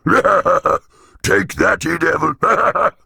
Sounds / Enemys / Giant
G_take_that.ogg